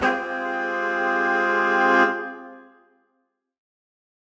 Index of /musicradar/undercover-samples/Horn Swells/C
UC_HornSwell_Csus4min6.wav